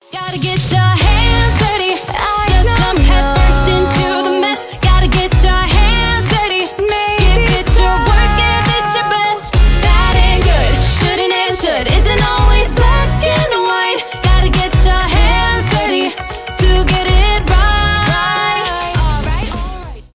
мюзикл
поп